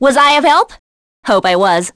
Yanne-Vox_Victory.wav